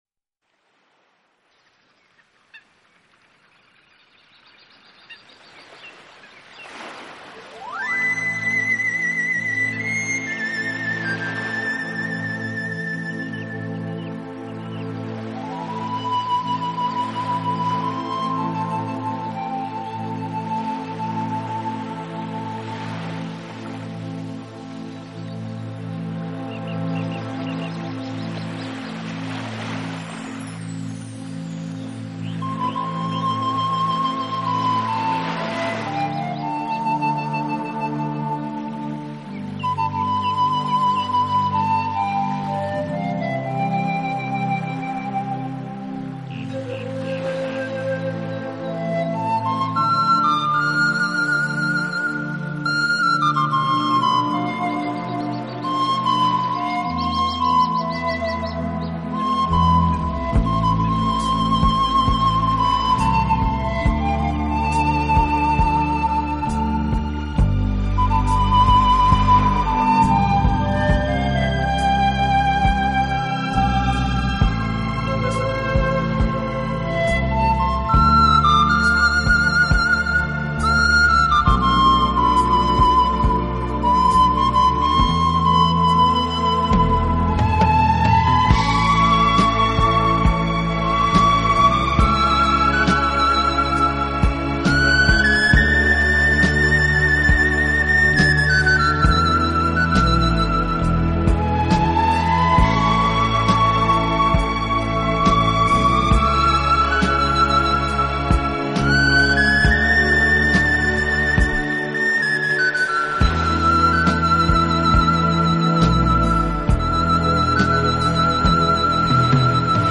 排箫的声音，清亮中含着暗哑，悠扬中带着迷朦，空旷中透出冥思，深情
用排箫演绎西洋名曲，更充分地表现了含蓄、柔美的东方气质，